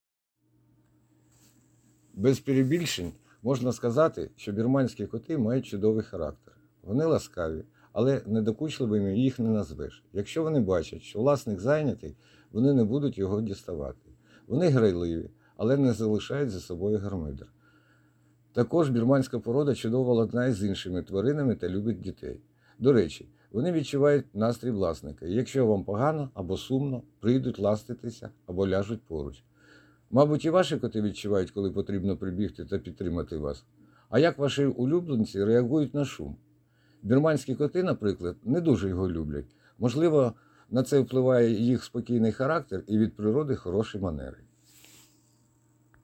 Озвучка о животных для ютуба.m4a